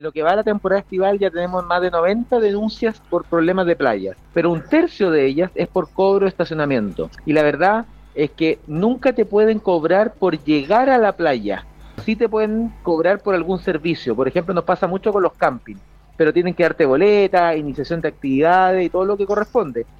En conversación con Radio Bío Bío, el ministro de Bienes Nacionales, Julio Isamit, se refirió a la polémica por la utilización pública de la playa de Metri en Puerto Montt.